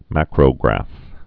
(măkrō-grăf)